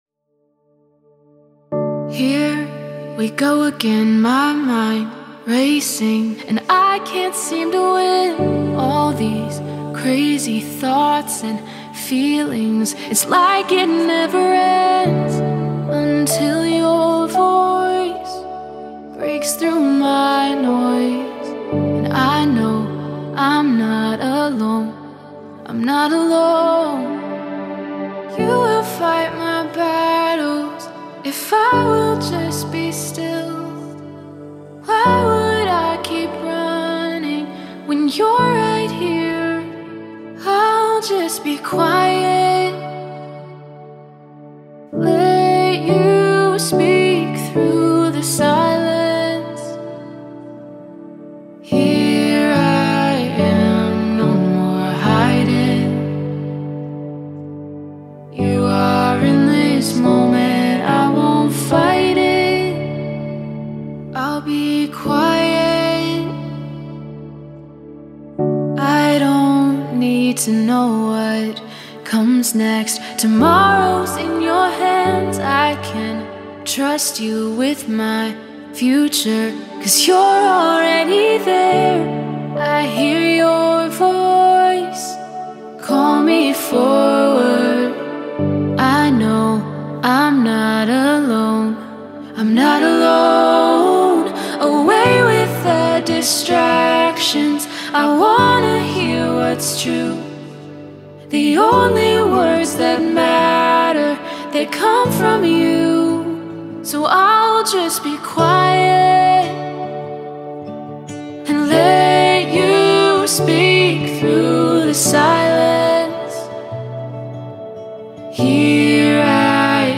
1. Sunday Worship – First Song: